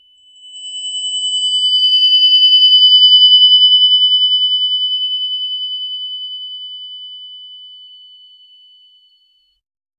Royalty-free vibraphone sound effects
single-vibraphone-chime-m-k7r6xgg4.wav